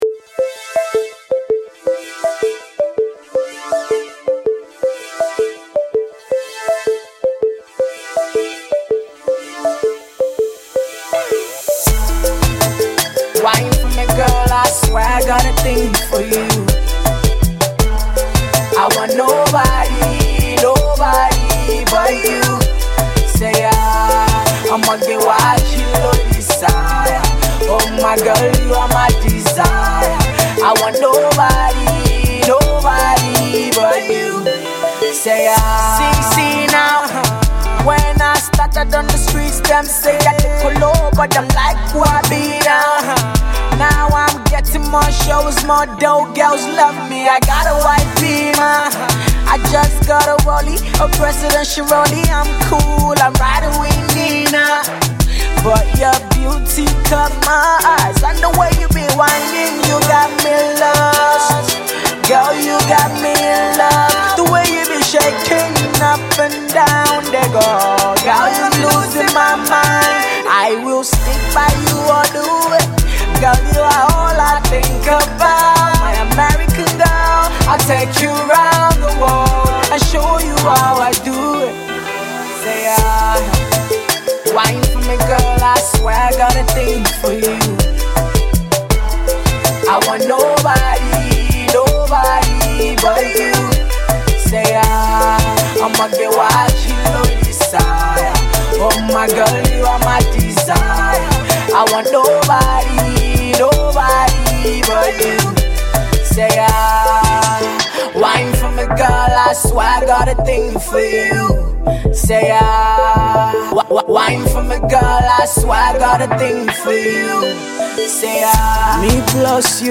This one’s a love song and it was recorded about a year ago.